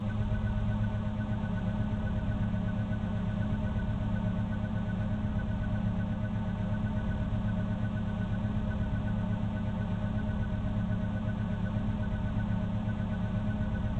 PortalNonMagical.wav